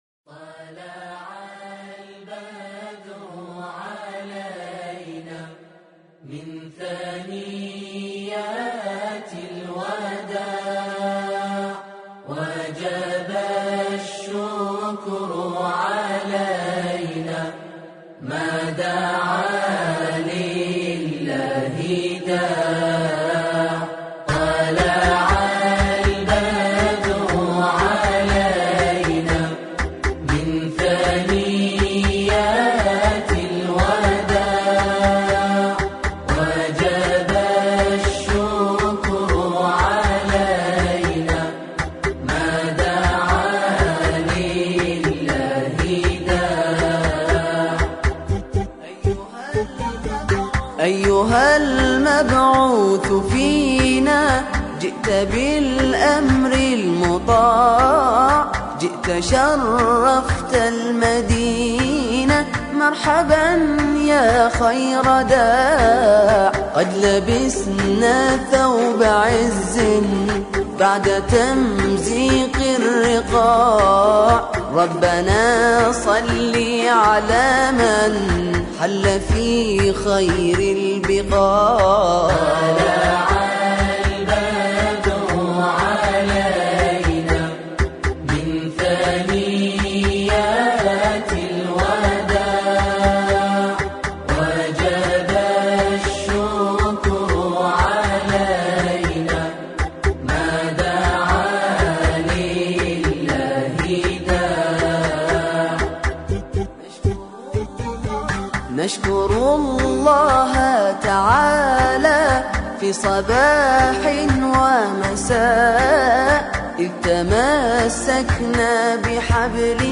دانلود تواشیح عربی